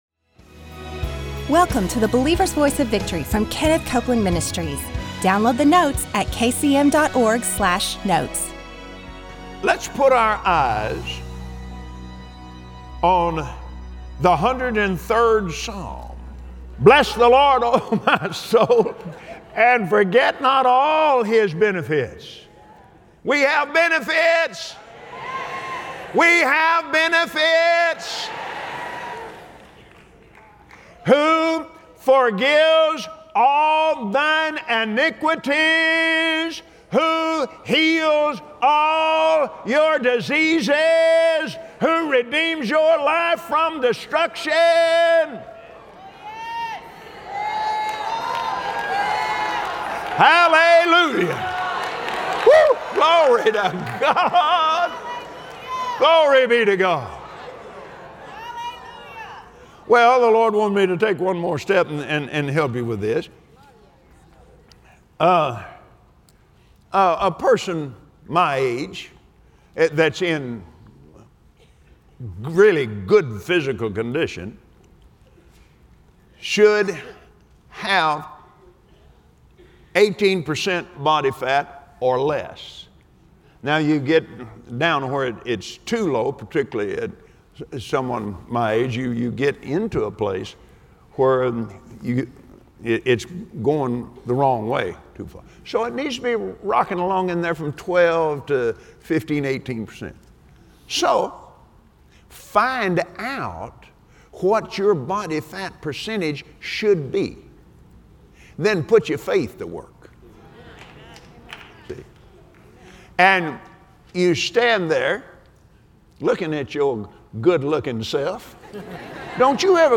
Join Kenneth Copeland on the Believer’s Voice of Victory as he teaches us how to receive the benefits of that covenant and live a strong, healthy life. Your healing is available to you today.